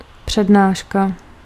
Ääntäminen
IPA: /dis.kuʁ/